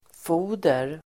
Uttal: [f'o:der]